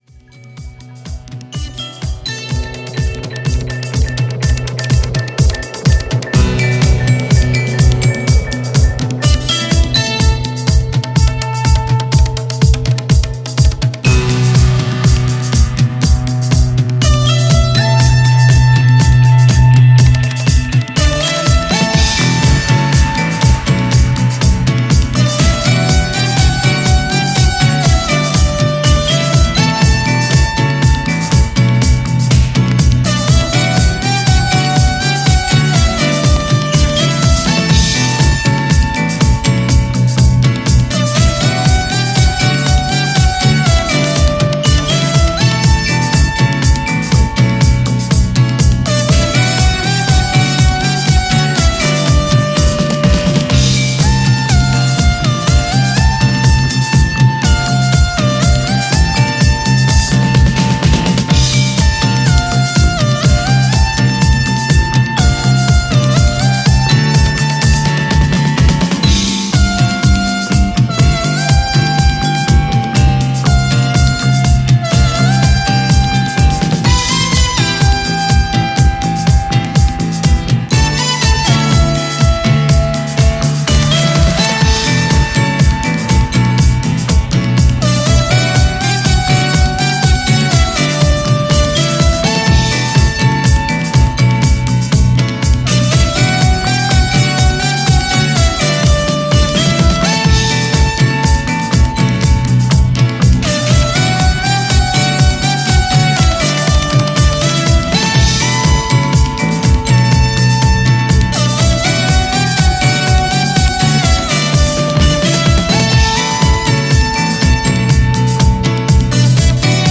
pumping spacey, Italo influenced magic